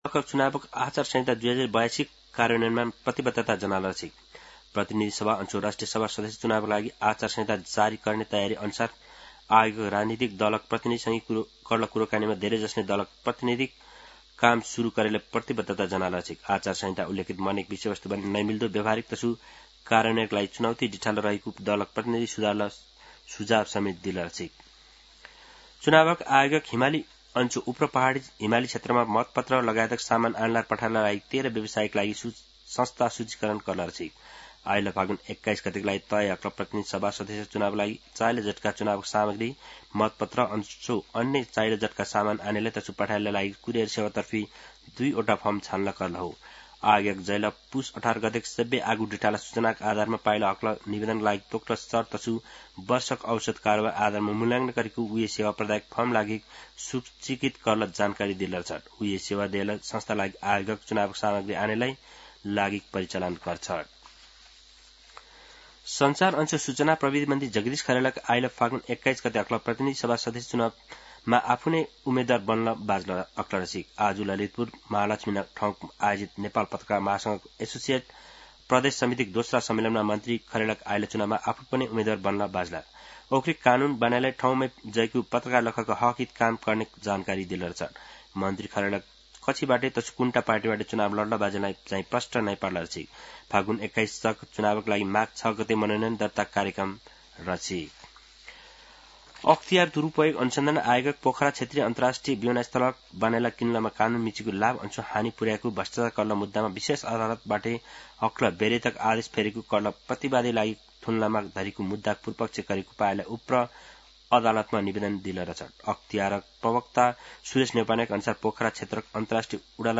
दनुवार भाषामा समाचार : ३ माघ , २०८२
Danuwar-News-2.mp3